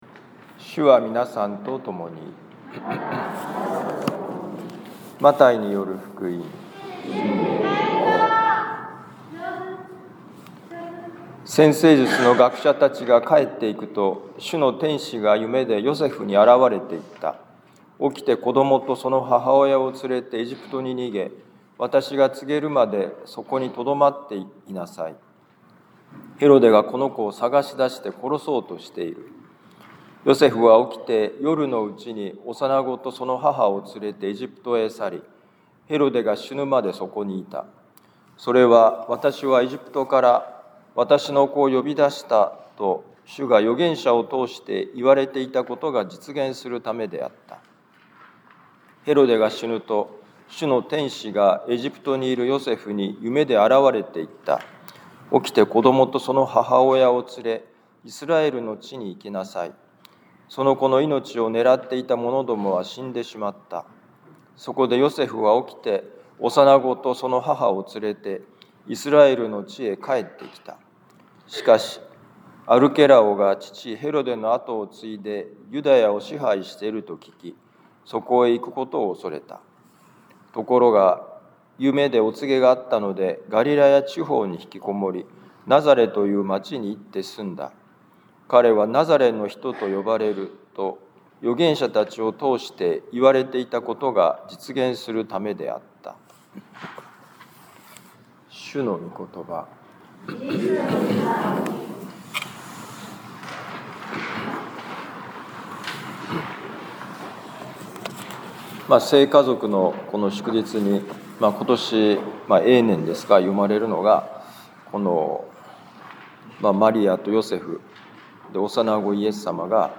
マタイ福音書2章13-15節、19-23節「辛い時にこそ共に歩む」2025年12月28日聖家族のミサ 防府カトリック教会